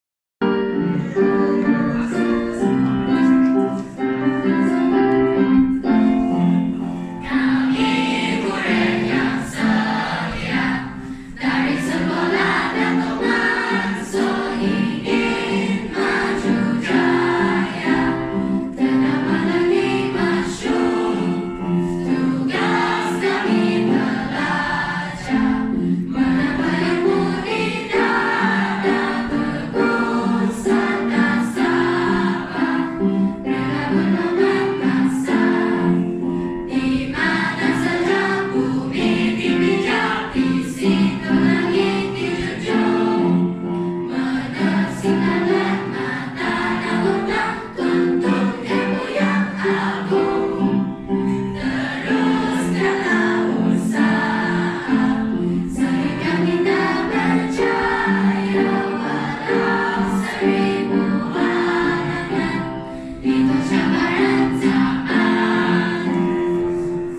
Lagu Sekolah